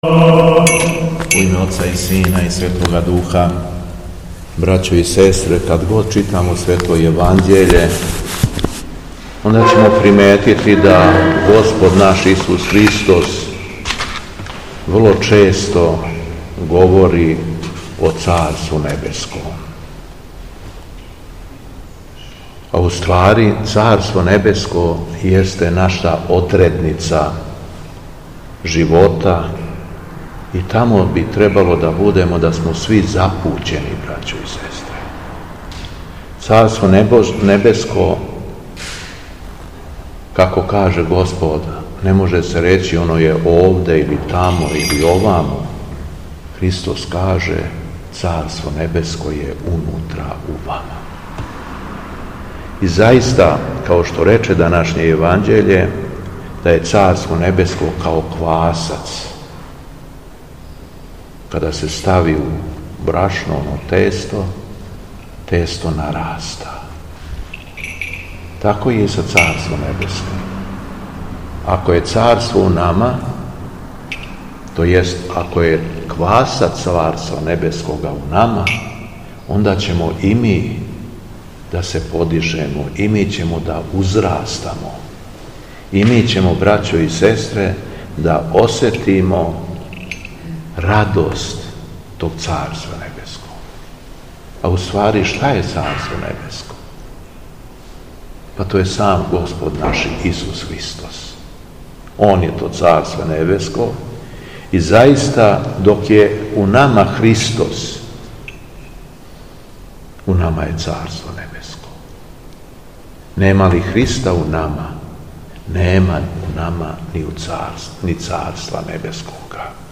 Беседа Његовог Високопреосвештенства Митрополита шумадијског г. Јована
Након прочитаног јеванђеља Митрополит се обратио сакупљеном народу: